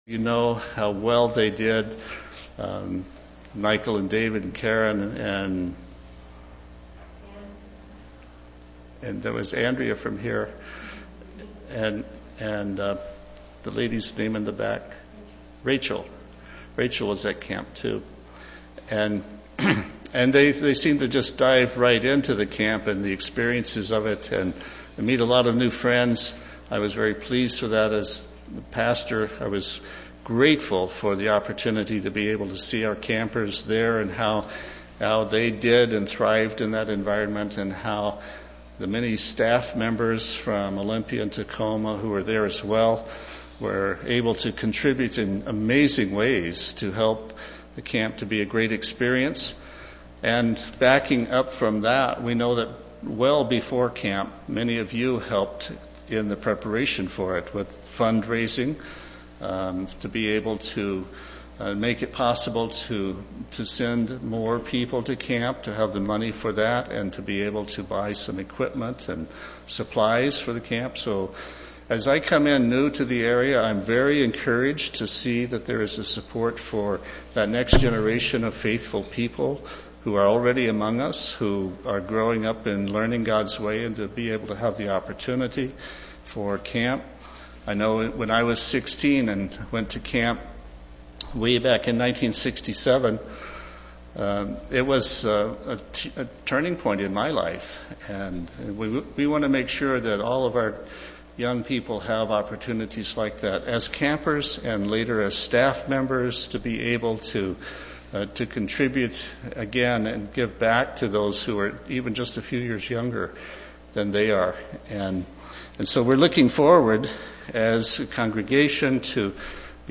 Given in Olympia, WA
UCG Sermon Studying the bible?